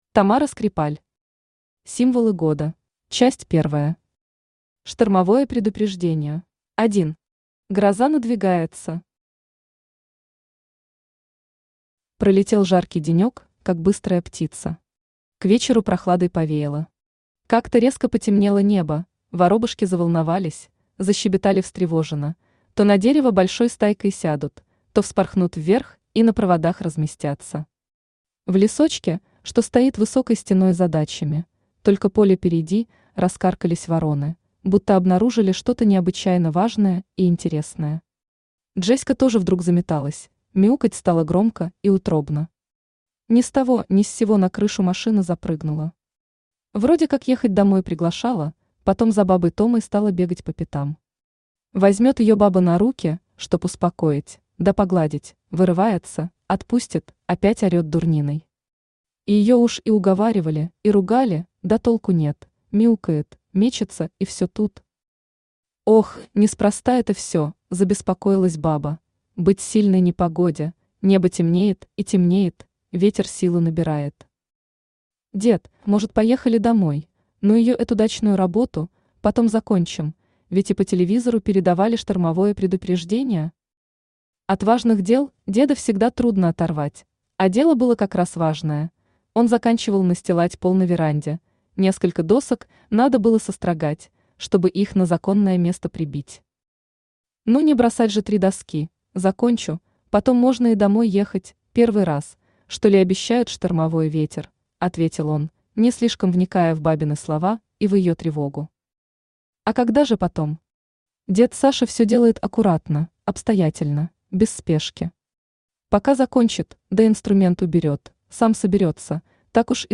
Аудиокнига Символы года | Библиотека аудиокниг
Aудиокнига Символы года Автор Тамара Антоновна Скрипаль Читает аудиокнигу Авточтец ЛитРес.